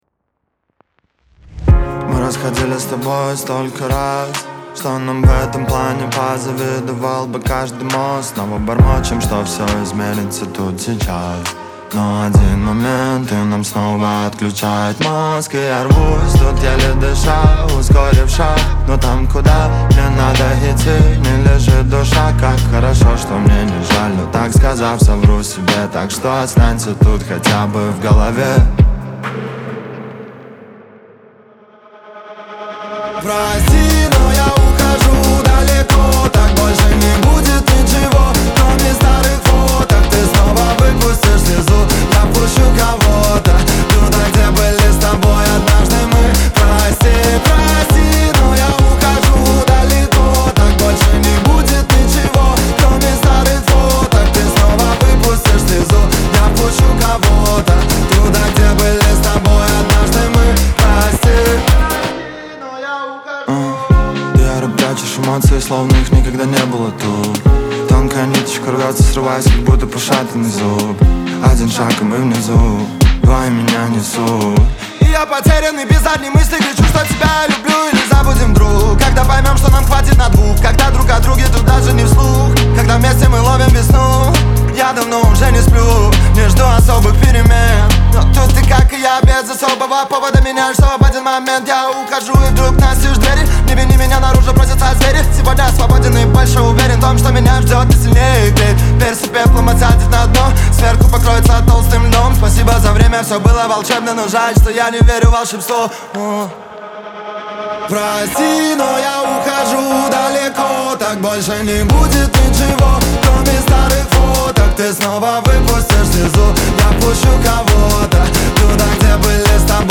ХАУС-РЭП , эстрада